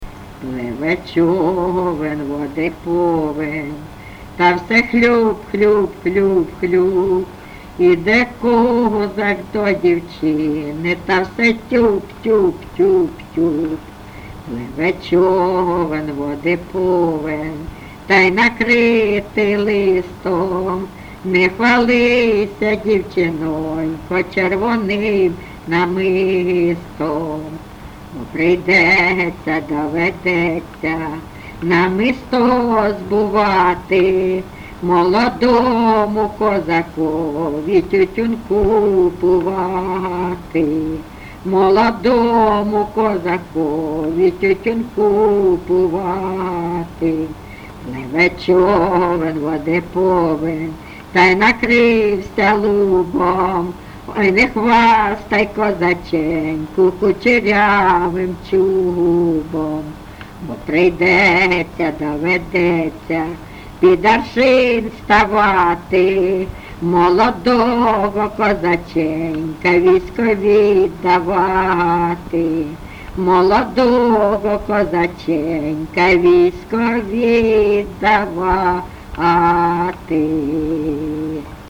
ЖанрПісні з особистого та родинного життя, Козацькі
Місце записус. Привілля, Словʼянський (Краматорський) район, Донецька обл., Україна, Слобожанщина